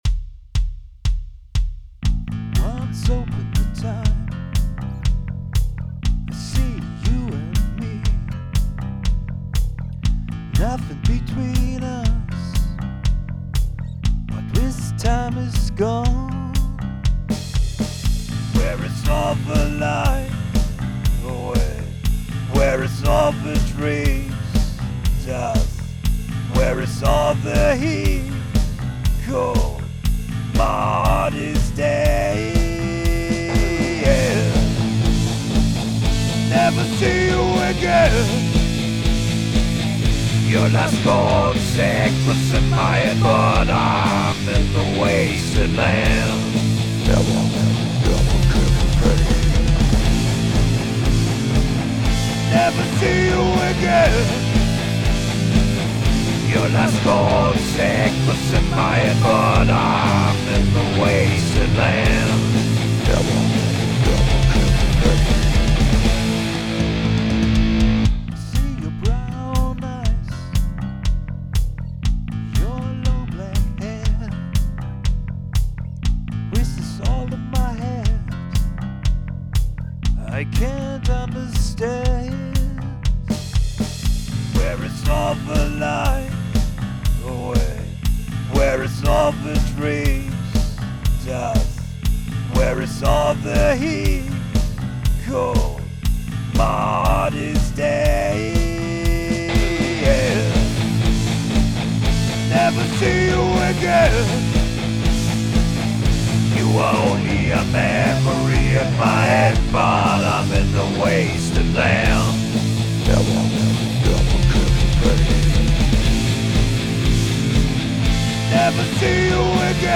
Angefangen hat es hier mit der Anfangspassage (Die akustischen Gitarren) um die ich dann den Song aufgebaut habe. Der ganze Song sollte etwas wütend, trotzig, hin und hergerissen klingen aber nicht negativ. So kamm ich dann zu der Geschichte die wohl jeder kennt: Der Schmerz nach einer zerbrochenen Beziehung.